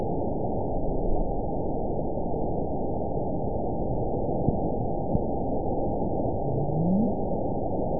event 921934 date 12/23/24 time 02:46:15 GMT (4 months, 2 weeks ago) score 9.20 location TSS-AB04 detected by nrw target species NRW annotations +NRW Spectrogram: Frequency (kHz) vs. Time (s) audio not available .wav